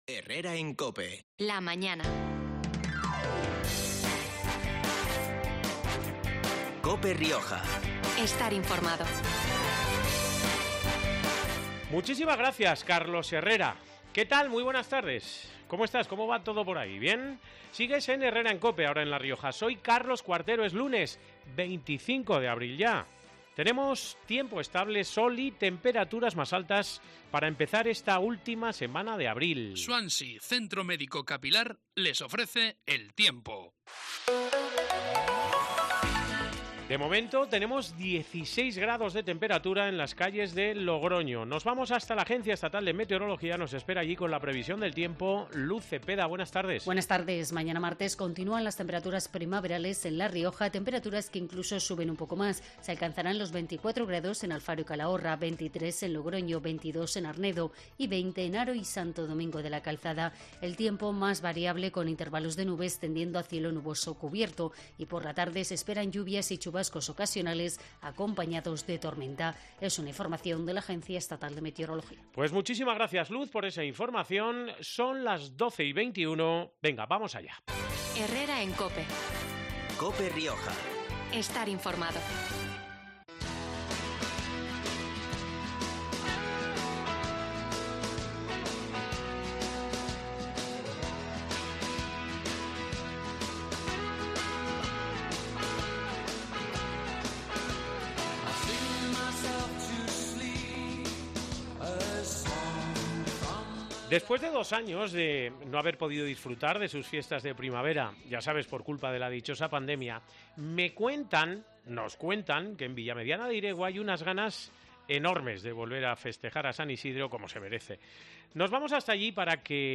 Nos lo ha confirmado este último lunes de abril en COPE Rioja la primera edil de la localidad, Ana Belén Martínez .